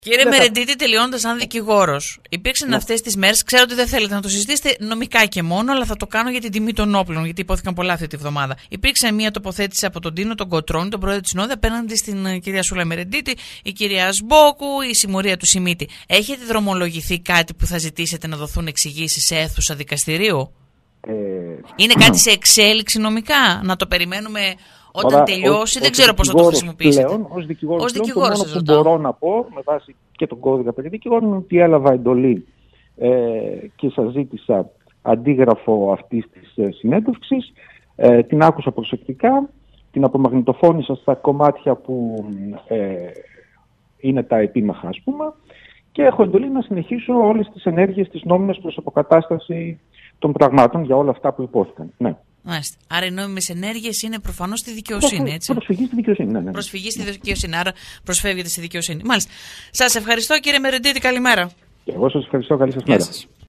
είπε στον αέρα της Λέσχης 97,6